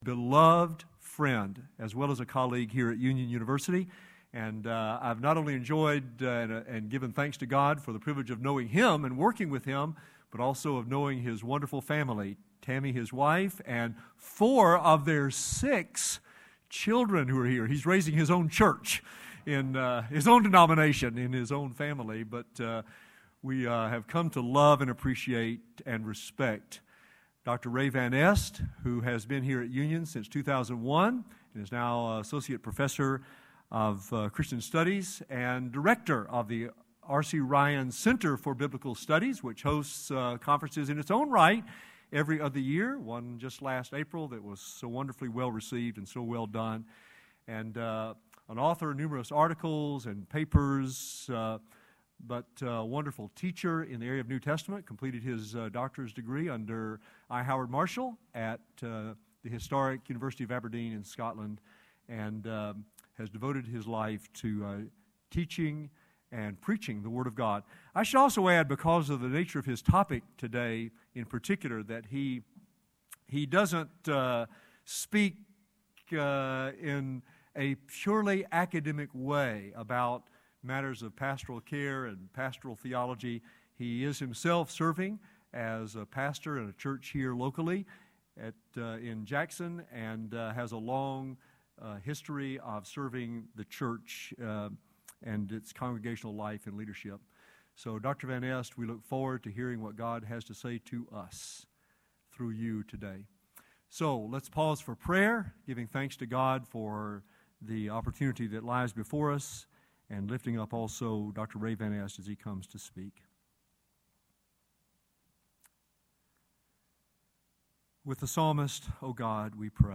Future of Denominationalism Conference